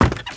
High Quality Footsteps
STEPS Wood, Creaky, Run 17.wav